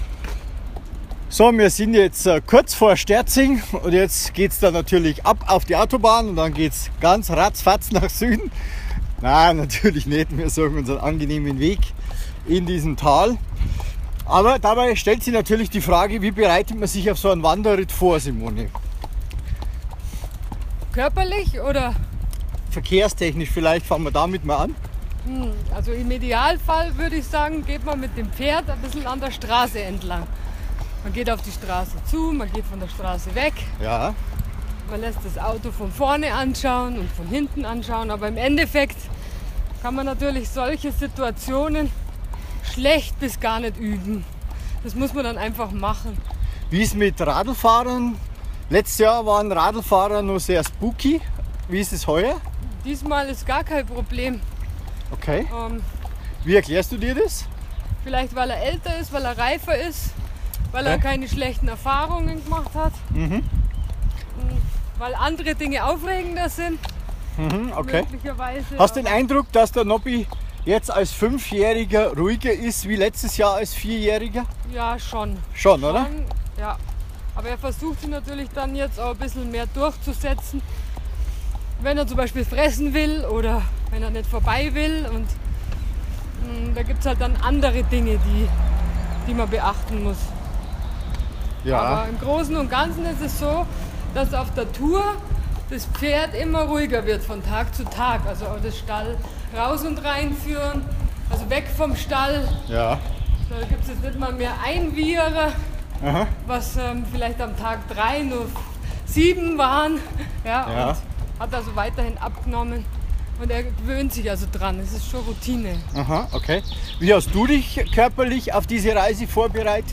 Interview: Wie Bereitet Man Sich Auf einen Wanderitt Vor